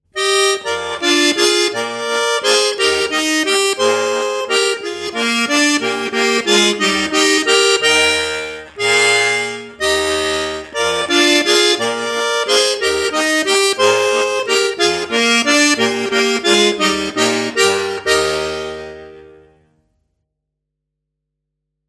Besetzung: Schwyzerörgeli mit CD